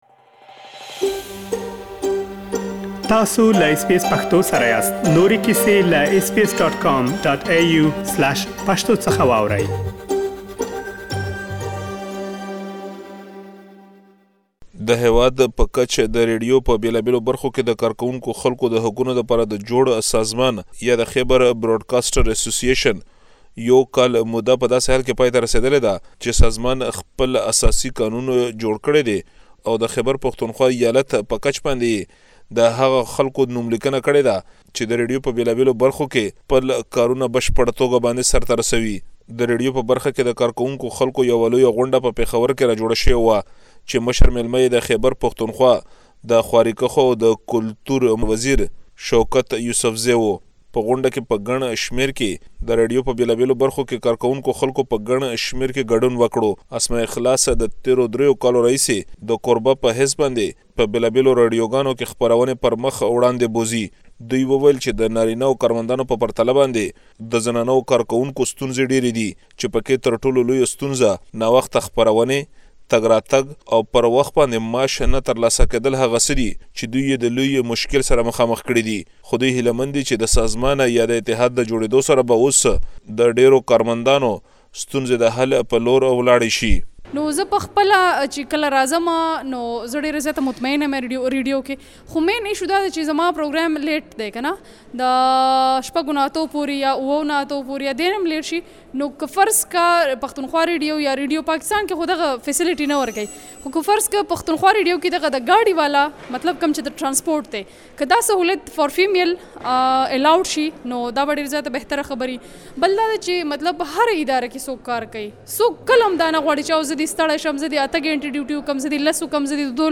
تاسو ته مو پيښور کې د خبريالانو غږونه راخيستي چې دا ټول پدې تيار شوي رپوټ کې اوريدلی شئ.